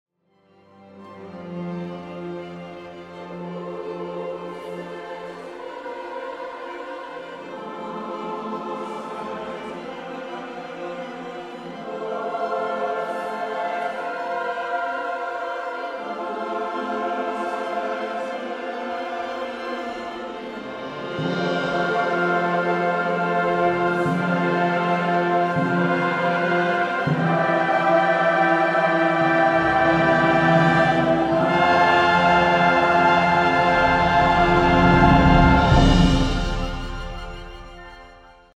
soprano saxophone soloist, choir